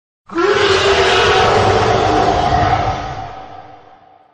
predator-roar_24733.mp3